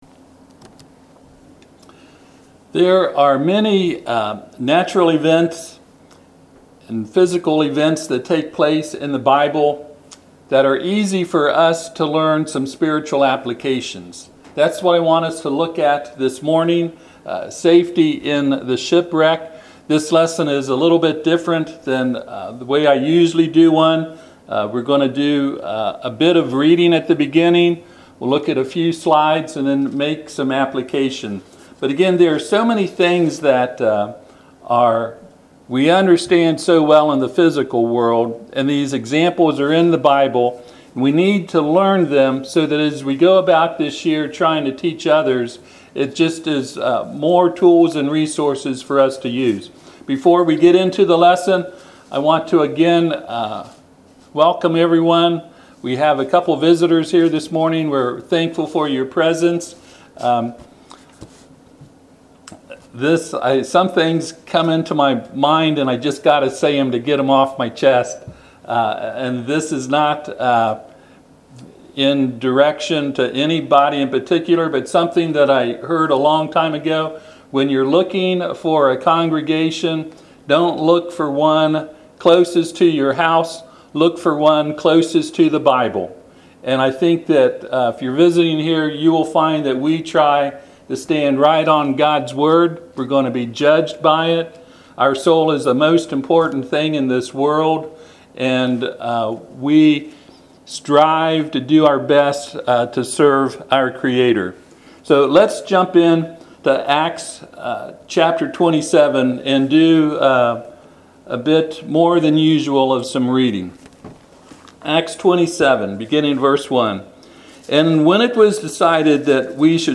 The shipwreck in Acts 27 is one such example. This sermon will examine that shipwreck and we will notice some comparisons between it and our soul’s salvation.